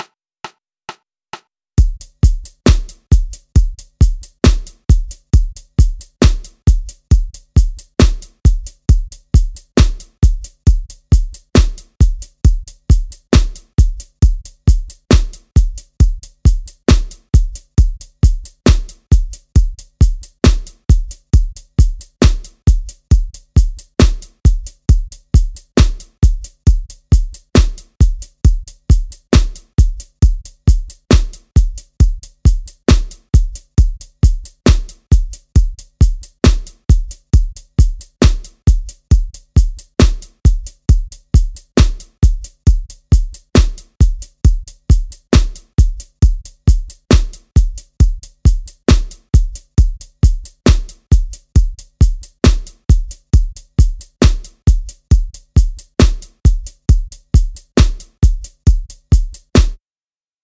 A vous de jouer ! Batterie / 135 Bpm
Le rythme de batterie utilisé ici est le « Rockers ».